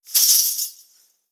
Danza árabe, bailarina da un golpe de cadera con un pañuelo de monedas 02
Sonidos: Acciones humanas